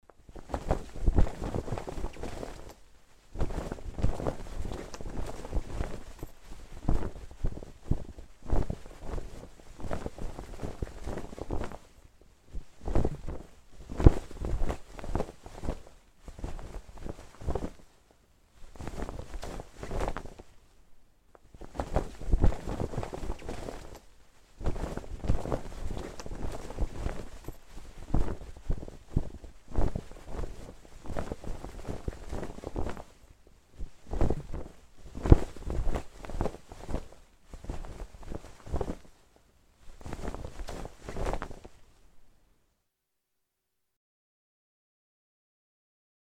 Звуки флага
Флаг гордо реет на ветру